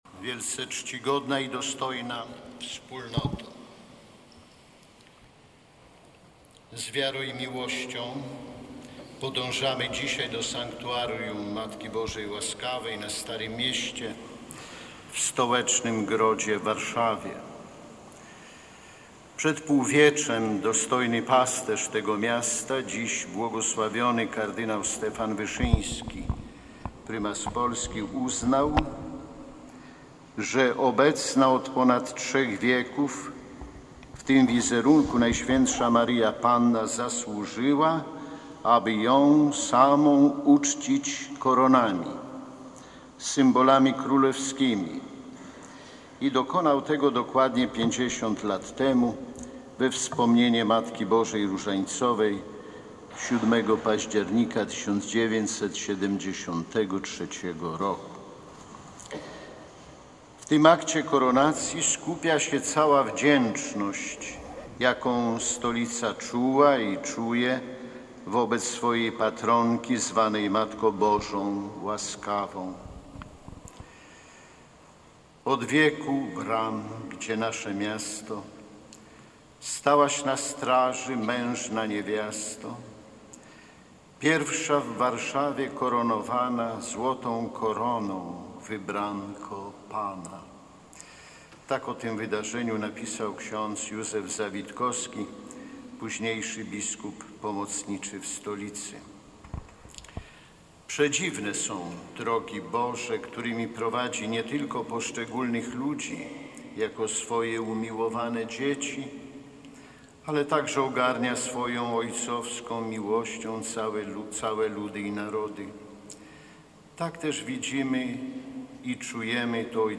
W kościele odbyła się rocznicowa Msza święta.
Homilia-bp-Romuald.mp3